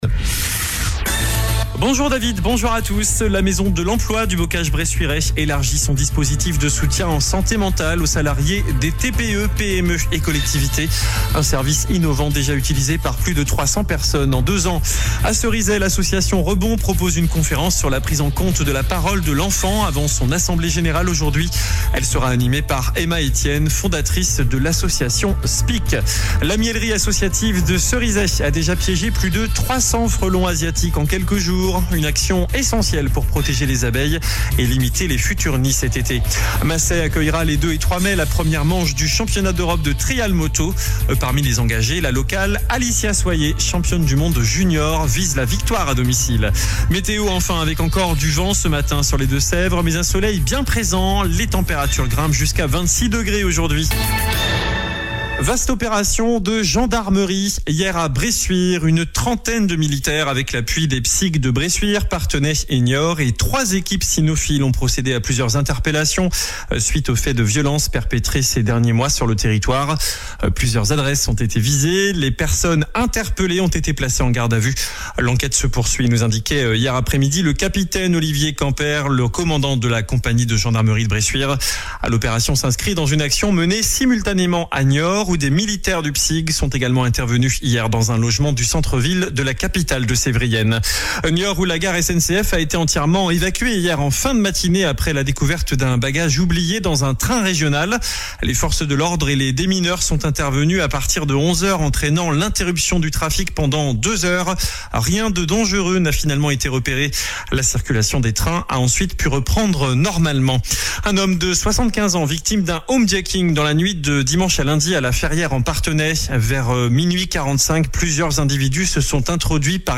JOURNAL DU JEUDI 23 AVRIL ( MIDI )